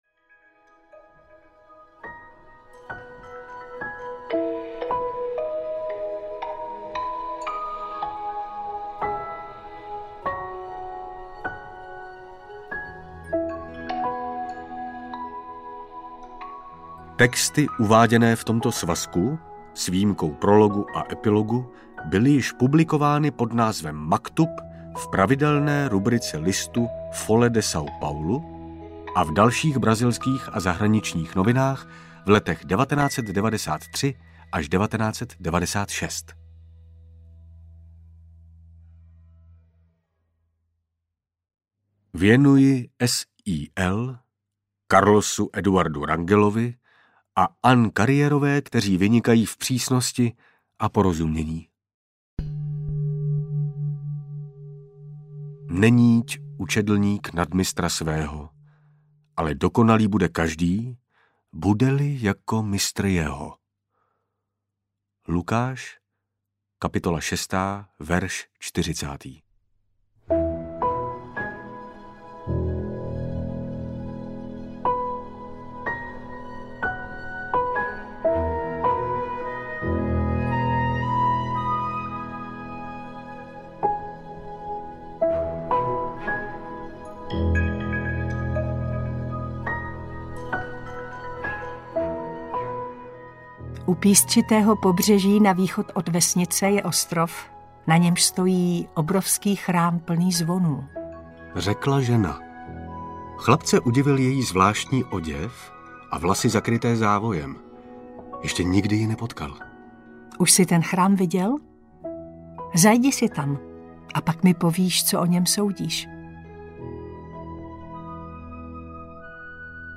Rukověť bojovníka světla audiokniha
Ukázka z knihy